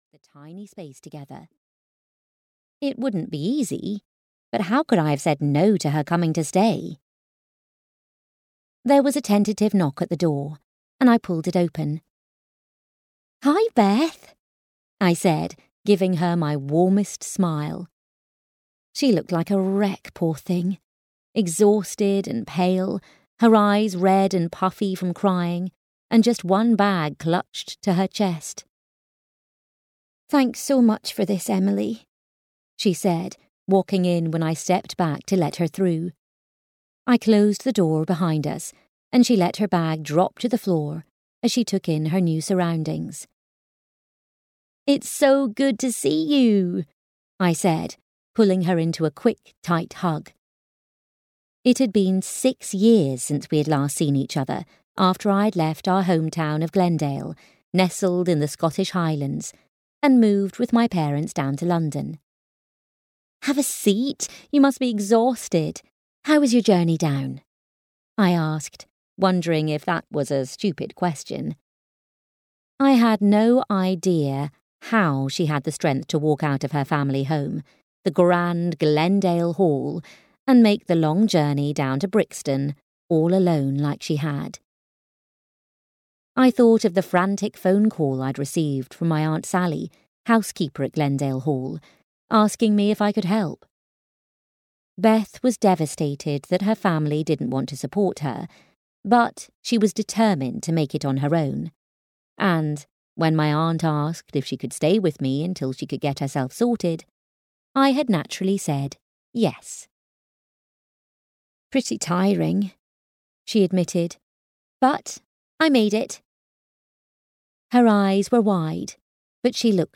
Audio knihaNew Beginnings at Glendale Hall (EN)
Ukázka z knihy